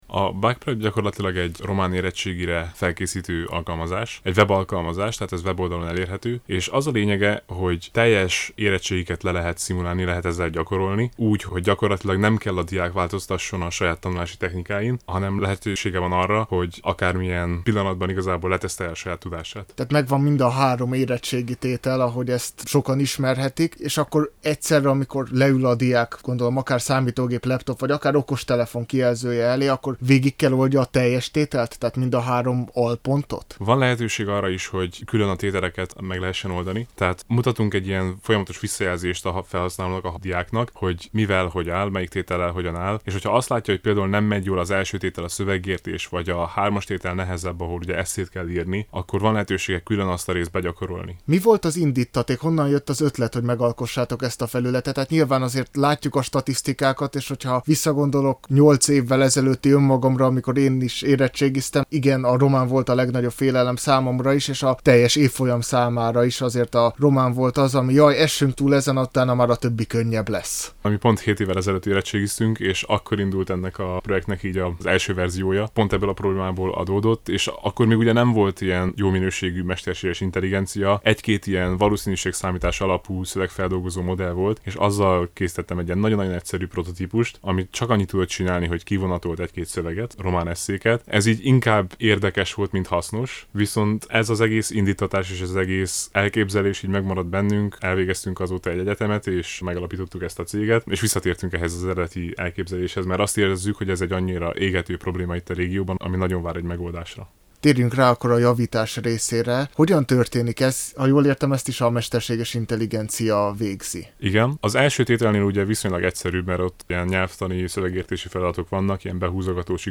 Amennyiben felkeltette az érdeklődésüket az imént elhangzott beszélgetés azt kedden, április 29-én, az este 8 órai híradó után, teljes hosszában meghallgathatják Digitális Világ című műsorunkban!